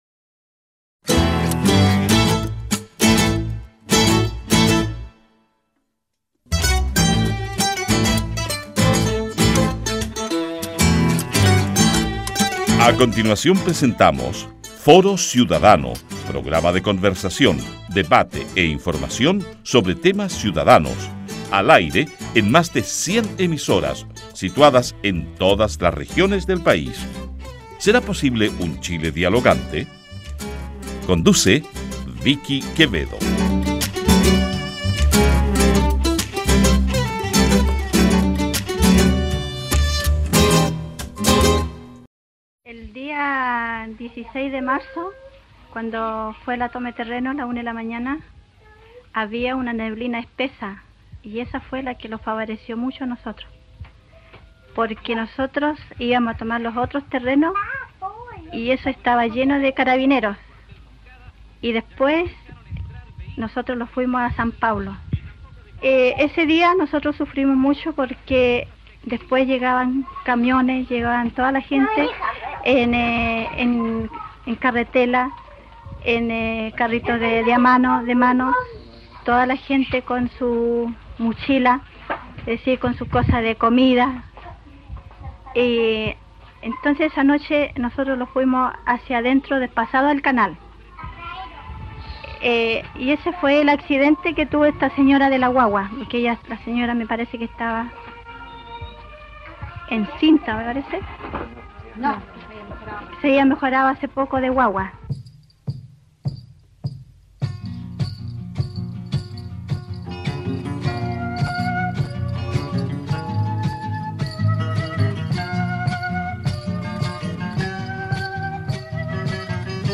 Esta toma nace en 2019, creció de manera significativa durante la pandemia, así como la capacidad organizativa de las 4.500 familias que allí viven, quienes han optado por la vía cooperativa para defender su derecho a la vivienda. Nuestro invitado de hoy ha trabajado estrechamente con el tema.